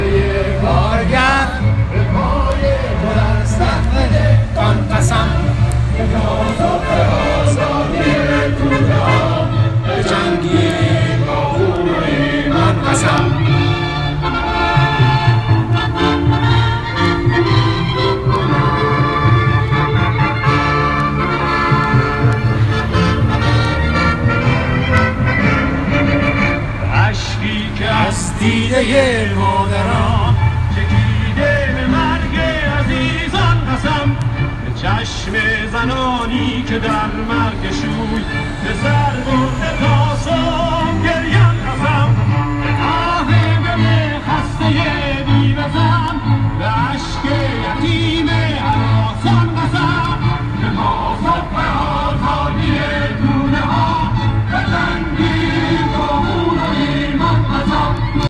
تورنتو
روز شنبه 22 آکست ایرانیان آزاده در شهرهای تونتو و اتاوا با برگزاری تظاهرات،  برای حمایت ازشورشگران  خواستار به محاکمه کشیدن عاملین قتل عام